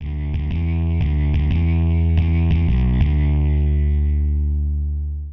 长型模糊低音90Bpm D
描述：用果味循环制作的摇滚模糊低音线
Tag: 90 bpm Rock Loops Bass Loops 918.89 KB wav Key : D